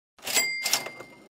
Звуки пополнения счета
Звук кассового аппарата